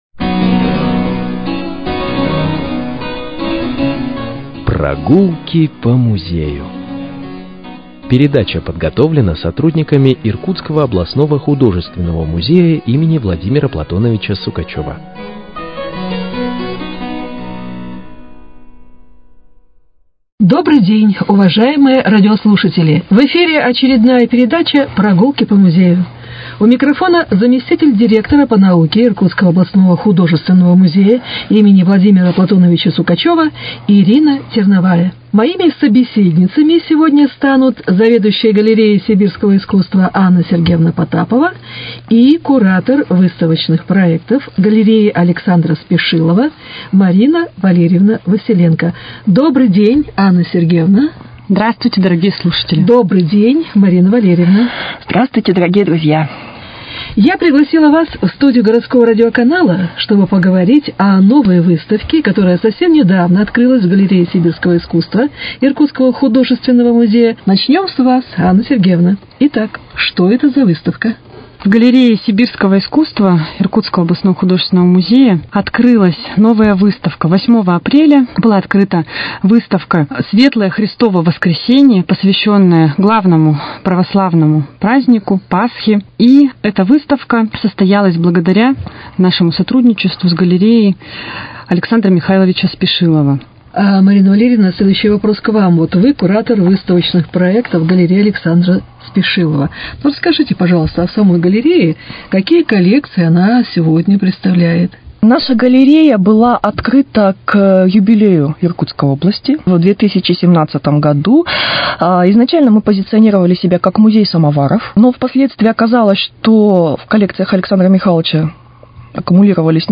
Передача